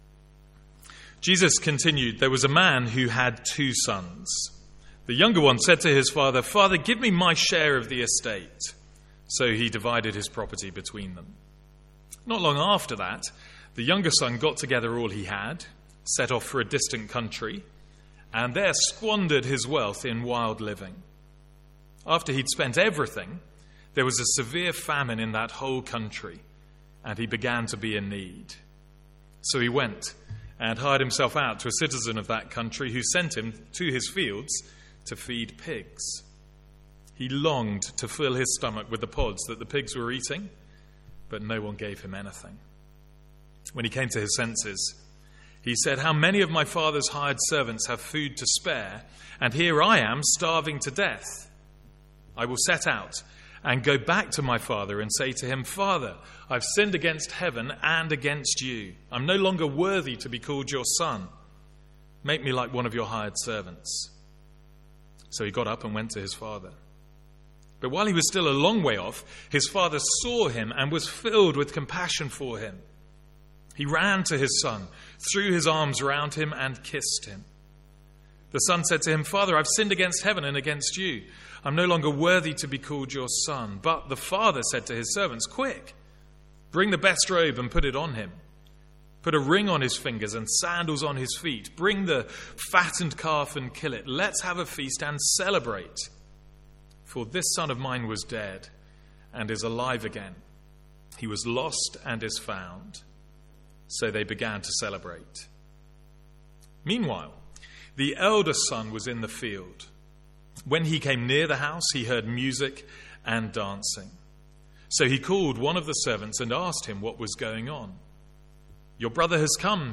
Sermons | St Andrews Free Church
From our morning series in Luke's Gospel.